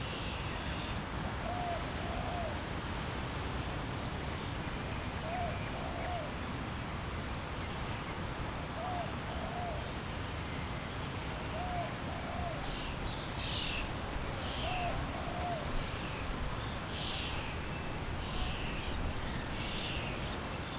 background_quiet_urban_park_loop_01.wav